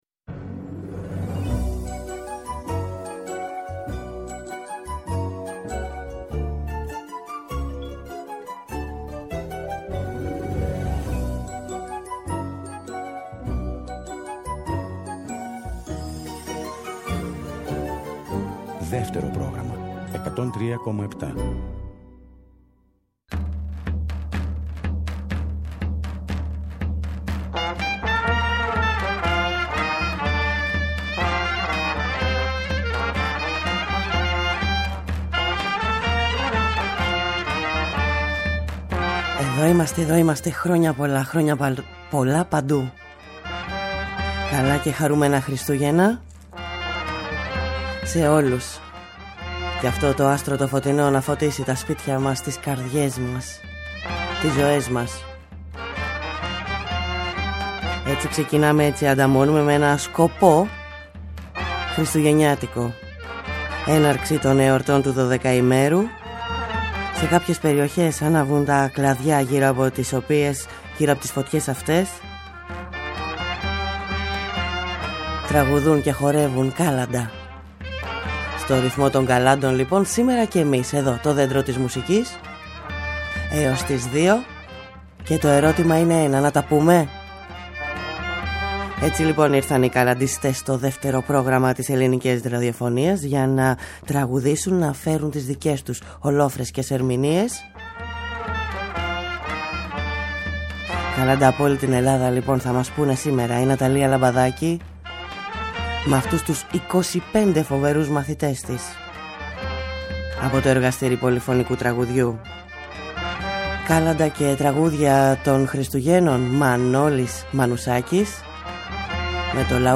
μας λένε τα Κάλαντα με νέες ηχογραφήσεις στα στούντιο της Ελληνικής Ραδιοφωνίας
στο λαούτο
στα κρουστά
25 φωνές χορωδίας πολυφωνικού τραγουδιού
στο ούτι και τη φωνή
στην κιθάρα και τη φωνή
Παραδοσιακη Μουσικη